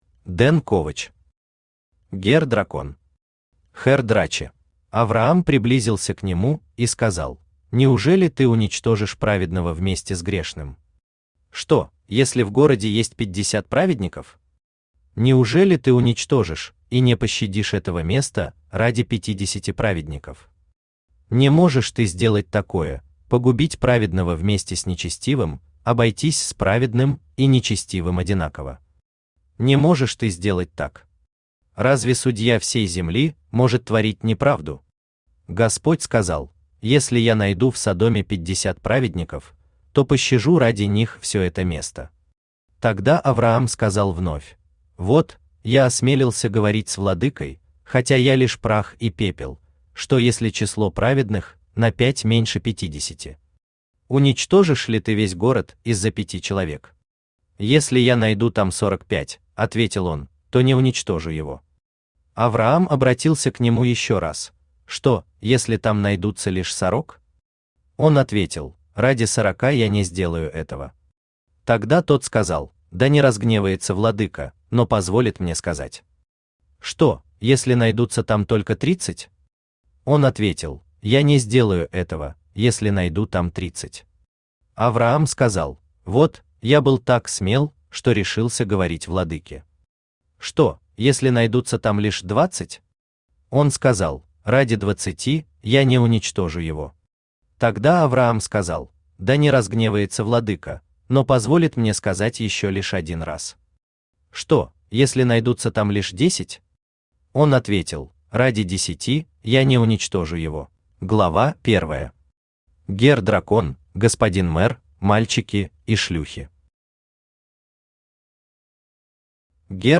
Аудиокнига Герр Дракон. Herr Drache | Библиотека аудиокниг
Herr Drache Автор Ден Ковач Читает аудиокнигу Авточтец ЛитРес.